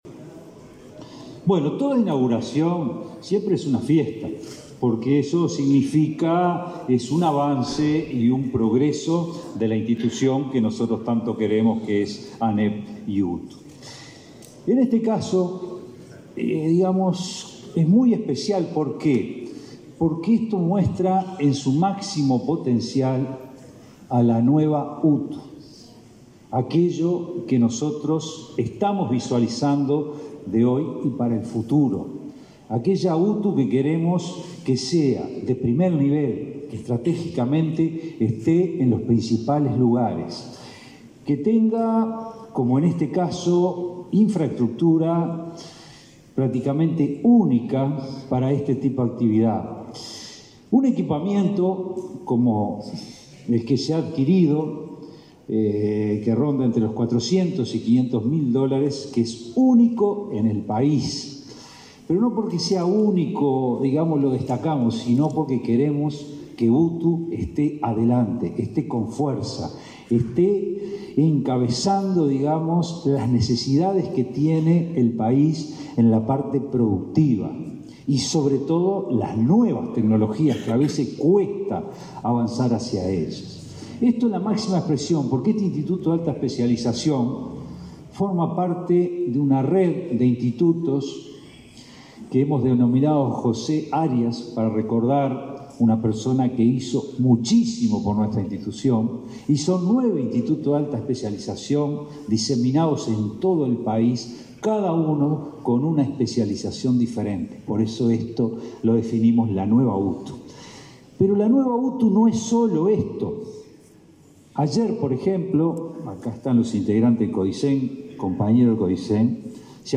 Palabra de autoridades de la educación en Canelones